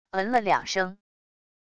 唔了两声wav音频